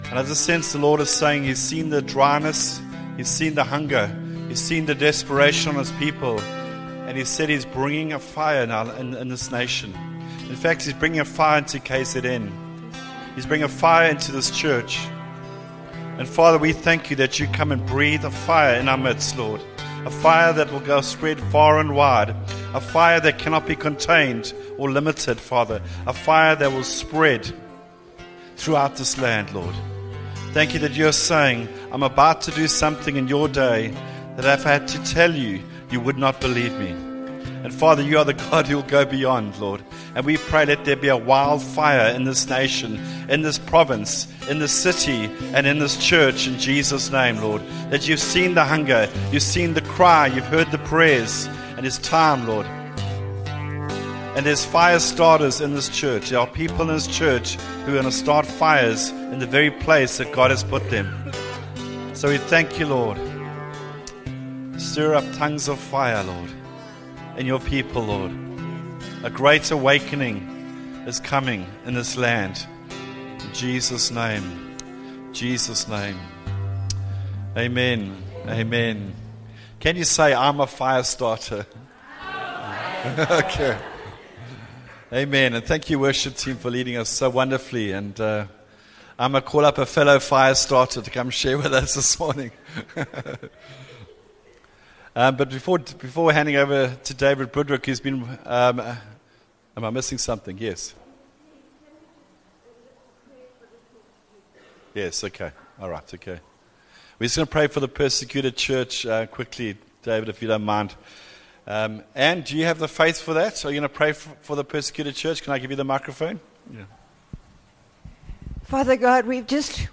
Upper Highway Vineyard Sunday messages